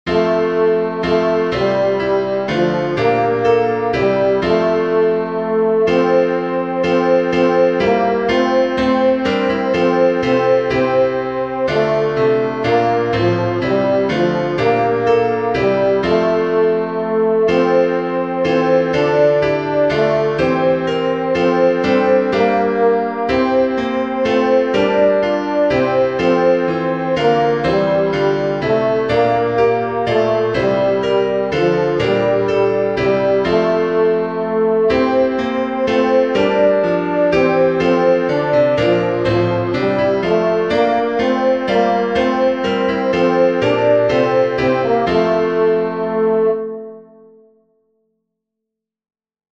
Tenor
“Holy God, We Praise Thy Name” (original German: “Großer Gott, wir loben dich”) is an ecumenical hymn.
grosser_gott_holy_god_we_praise-tenor.mp3